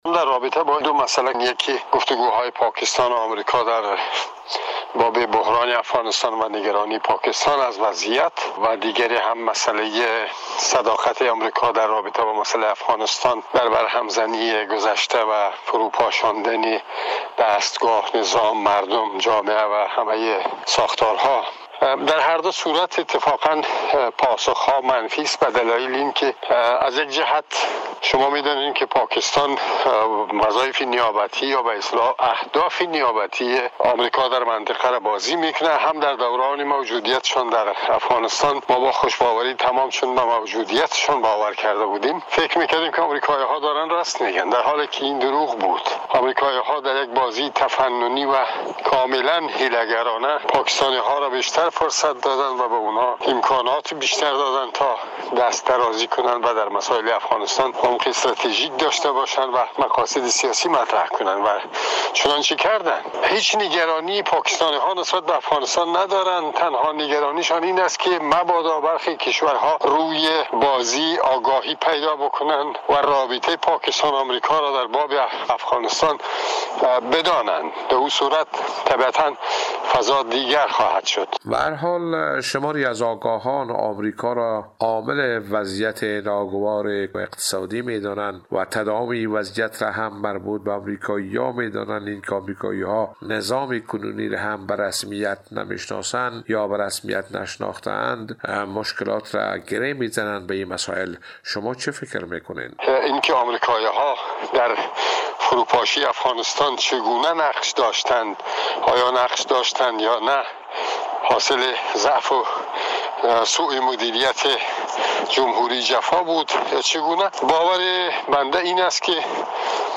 گفت و گو با خبرنگار رادیو دری